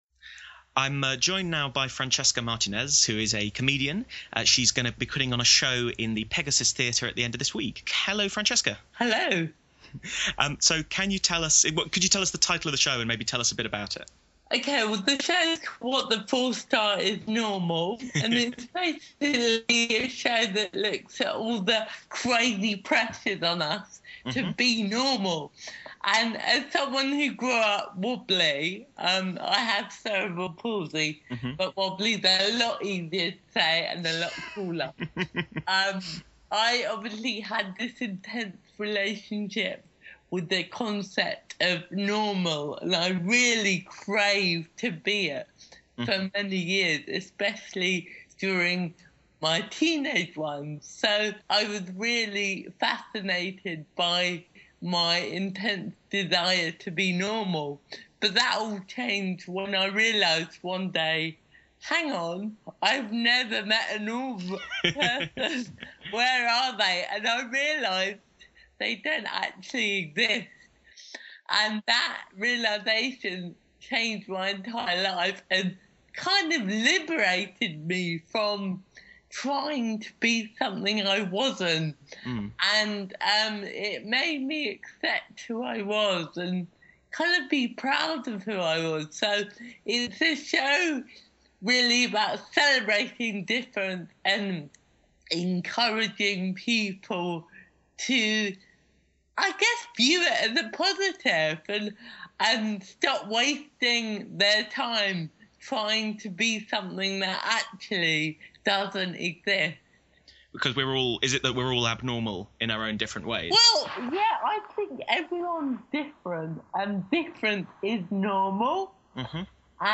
Francesca-Martinez-Interview.mp3